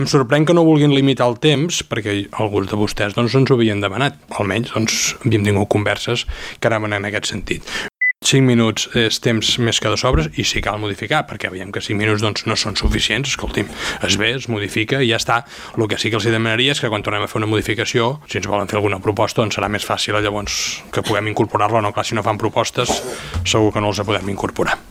Recuperem les seves intervencions durant la sessió d’abril:
L’alcalde Marc Buch va respondre als grups d’esquerres que la proposta recull les demandes que ja s’havien plantejat en converses prèvies, tot i que no s’havien formalitzat, i es va queixar de la manca d’aportacions.